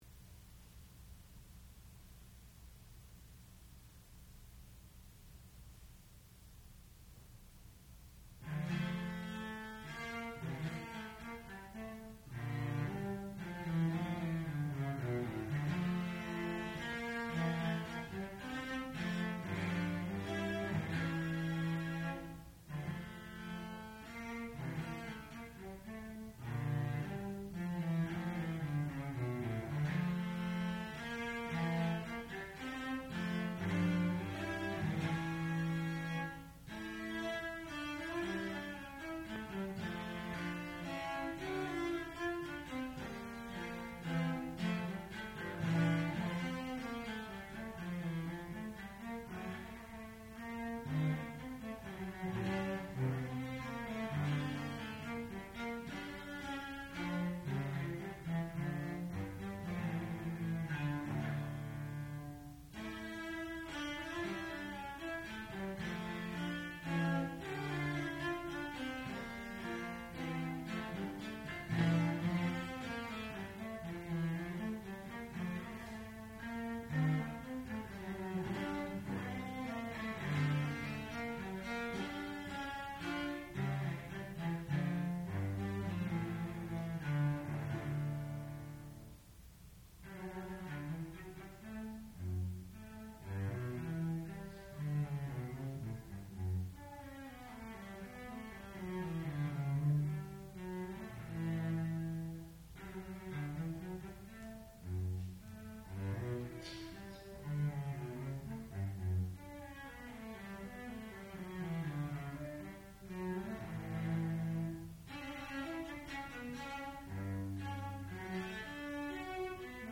sound recording-musical
classical music
Senior Recital
violoncello